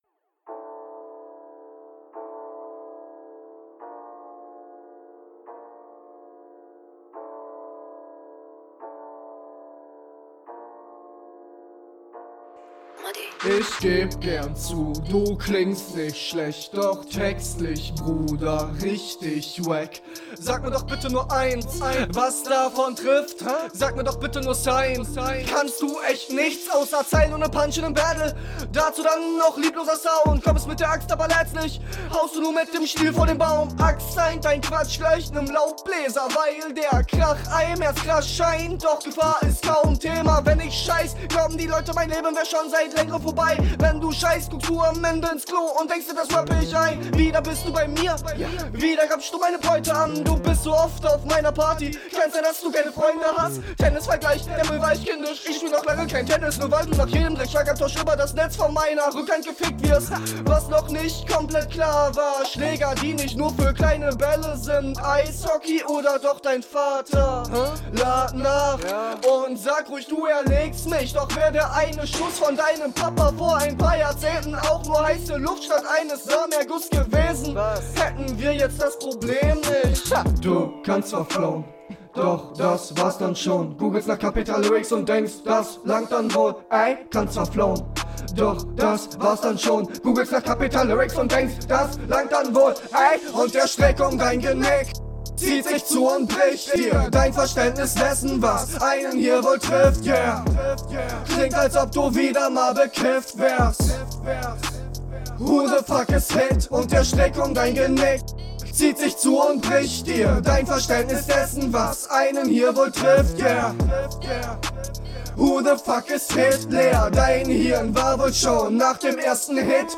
Hier aber wieder das Problem mit der Stimme.